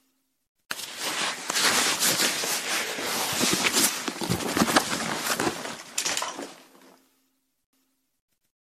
Tiếng Tháo, cởi bỏ Balo, Túi xách…
Thể loại: Tiếng đồ vật
Description: Đây là những âm thanh biểu thị sự cởi ra, gỡ bỏ, tháo xuống, bỏ hành lý, balo, túi xách, mang lại cảm giác nghỉ ngơi, giải tỏa, hoặc chuyển cảnh sau một hành trình.
tieng-thao-coi-bo-balo-tui-xach-www_tiengdong_com.mp3